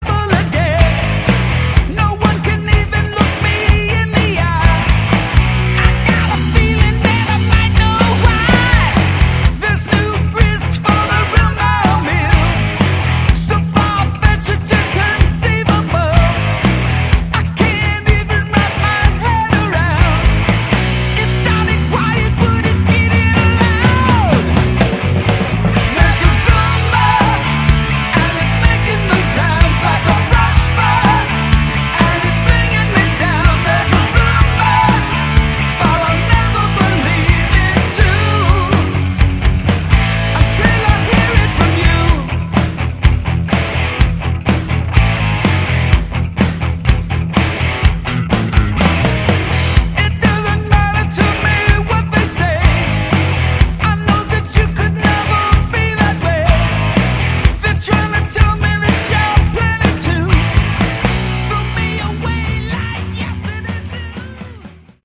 Category: Hard Rock
guitar, vocals
bass, vocals
drums